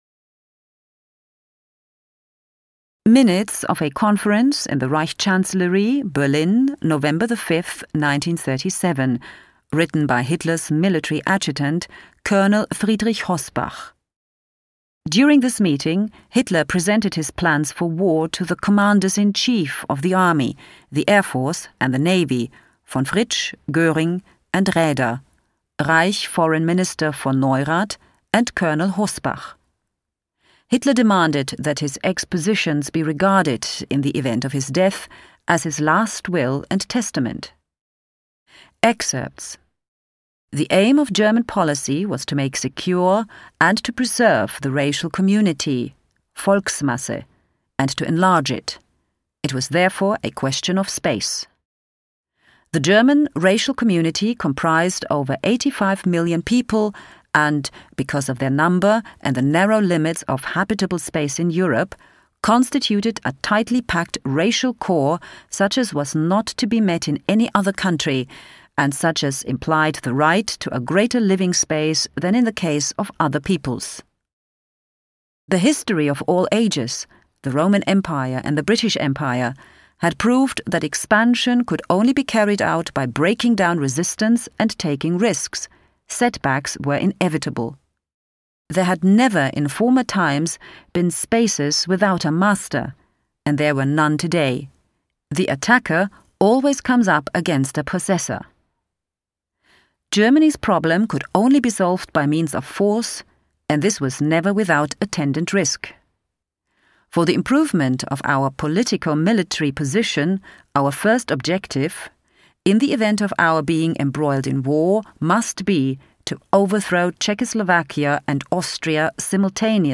Audioguide files